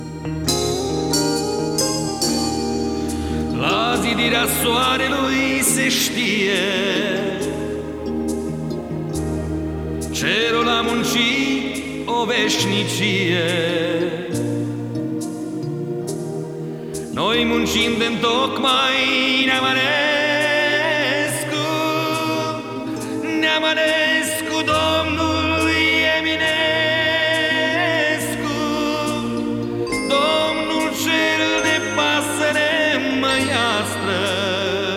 # Поп